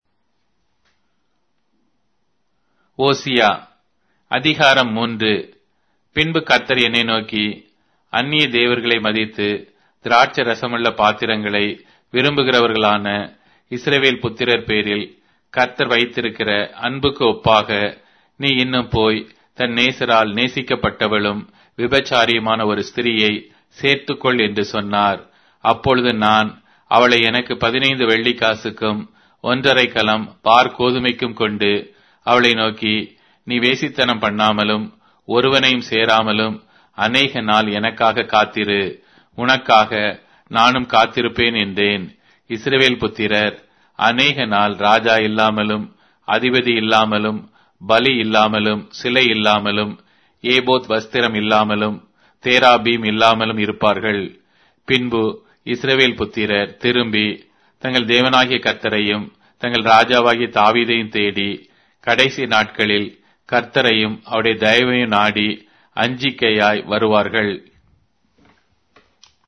Tamil Audio Bible - Hosea 11 in Nlv bible version